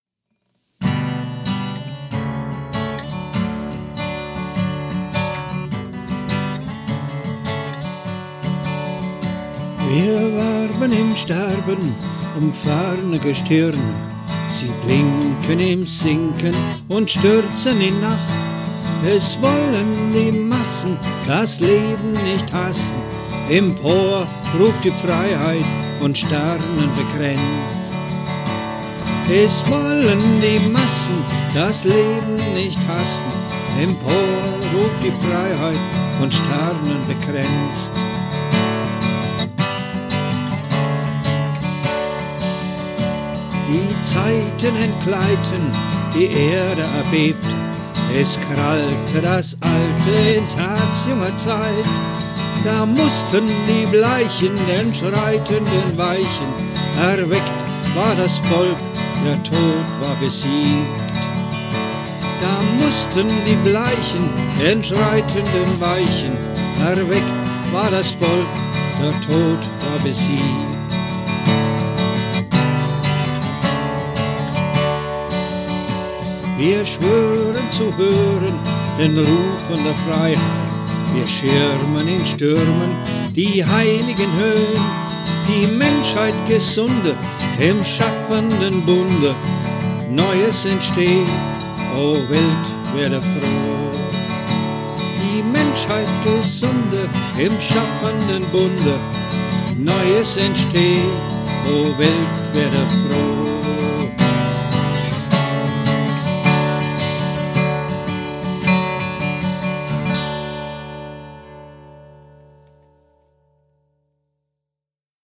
9.11. – Gedenkweg durch Vor- und Nachgeschichte des Pogroms Rund 100 Teilnehmer*innen zogen durch die Innenstadt – Redebeiträge und Lieder an vier Stationen – Neue Gedenktafel für Peter Gingold